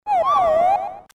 Play, download and share weird birds original sound button!!!!
weird-birds.mp3